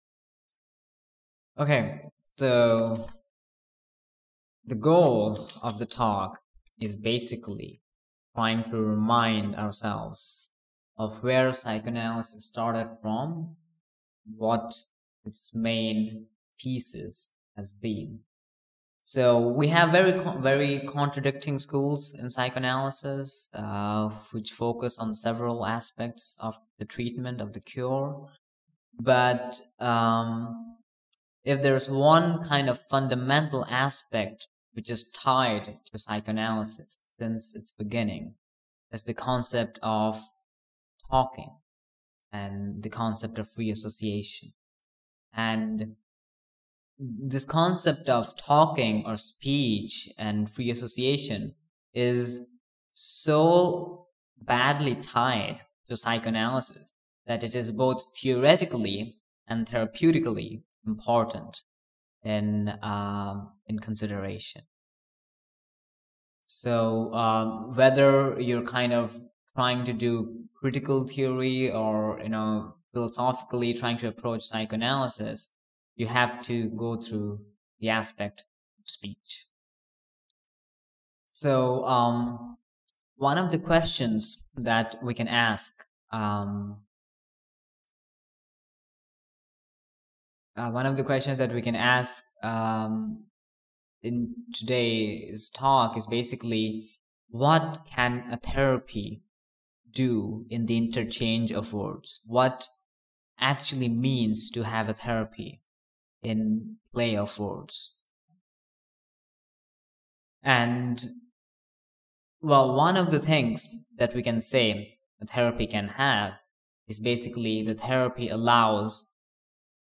This was a follow-up presentation to my last presentation on the analytic process. This was quite a scattered talk, but nonetheless the main points regarding the importance of speech were made along with a short clinical vignette which emphasises the importance of speech when problematic things like ambivalence and mourning during analysis.